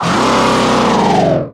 Cri de Sarmuraï dans Pokémon Soleil et Lune.